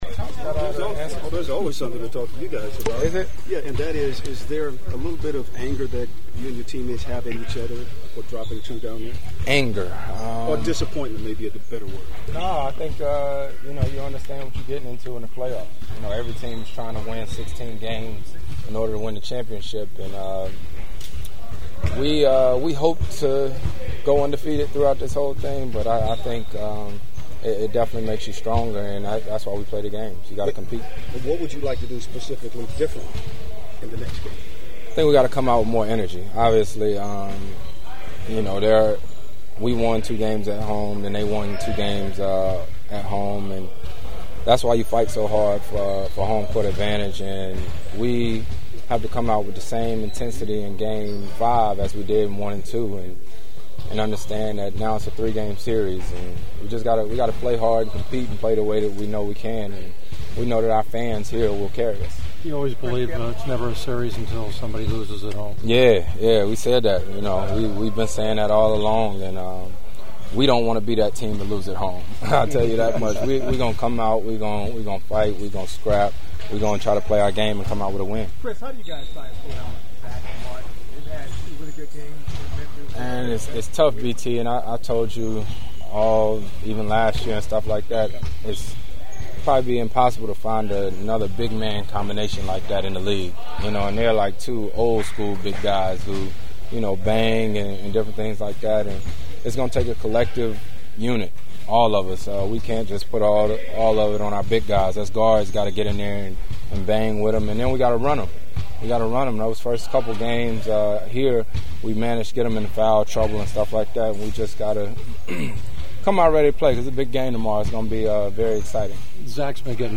The following are our after practice chats with the Clippers…
Chris Paul who was in a playoff-serious but happy mood: